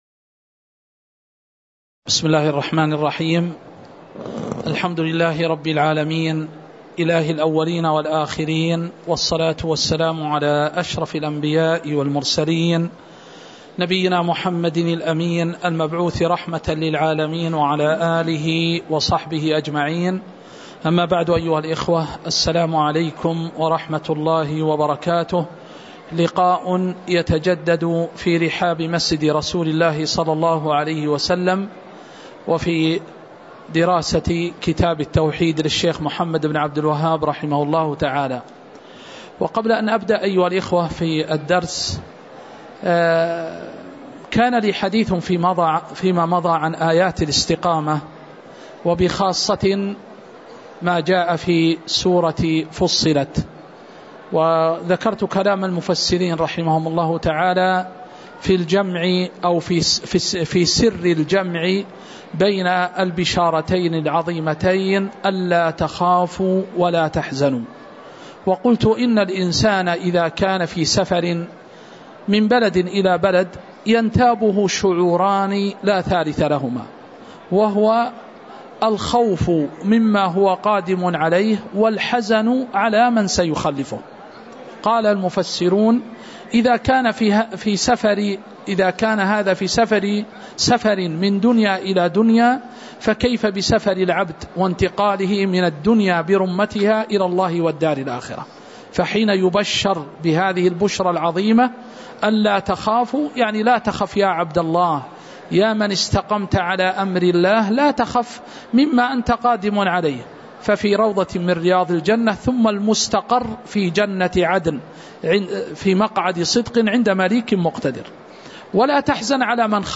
تاريخ النشر ١٥ شوال ١٤٤٠ هـ المكان: المسجد النبوي الشيخ